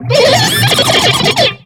Cri de Coatox dans Pokémon X et Y.